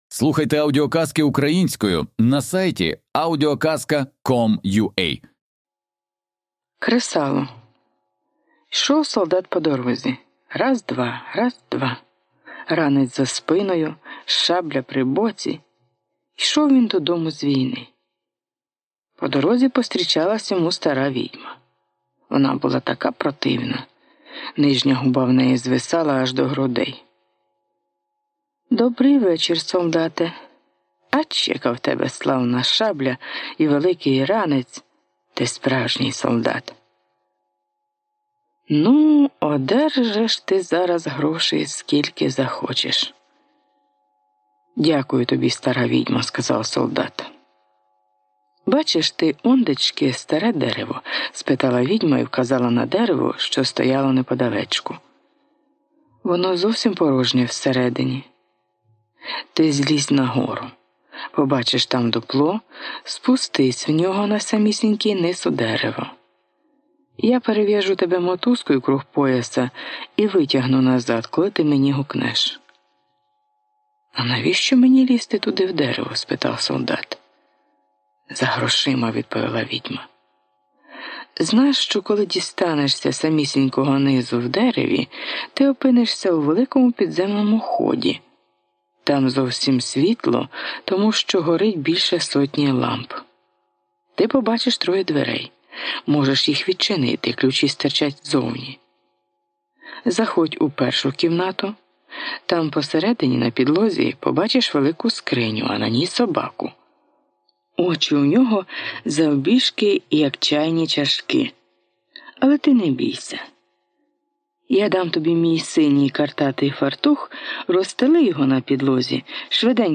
Аудіоказка Кресало